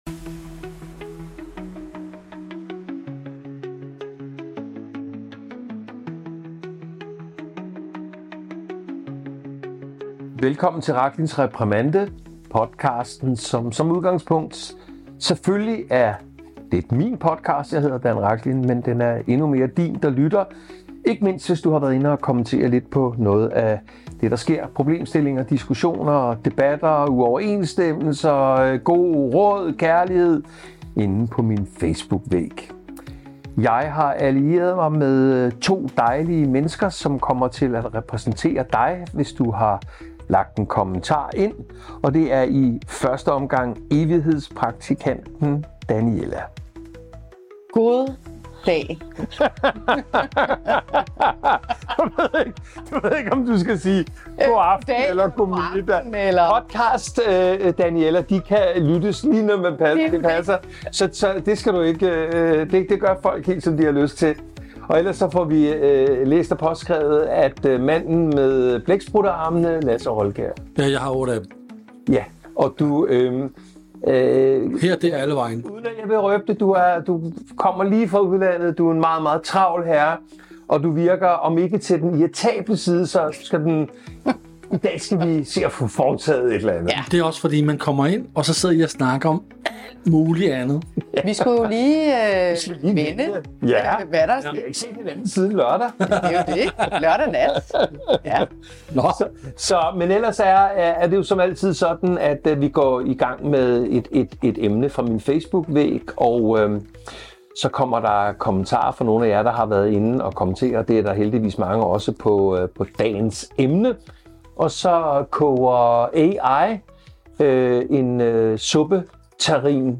En episode til dig, der vil tættere på virkeligheden bag ord som fattigdom, værdighed og næstekærlighed – uden filter og med både varme, humor og kant.